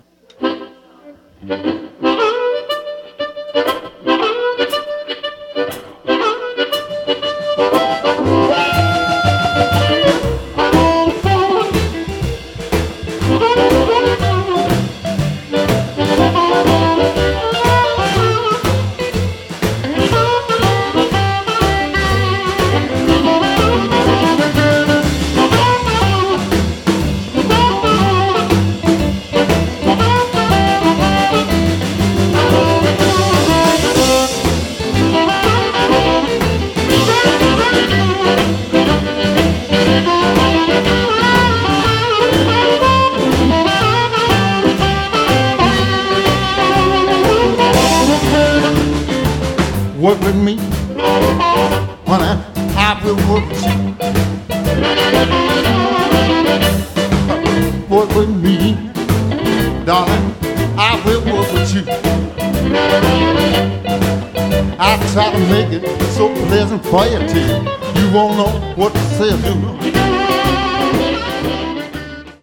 recorded live at Moe's Alley in Santa Cruz, California